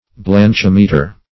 Search Result for " blanchimeter" : The Collaborative International Dictionary of English v.0.48: Blanchimeter \Blanch*im"e*ter\ (bl[.a]nch*[i^]m"[-e]*t[~e]r), n. [1st blanch + -meter.]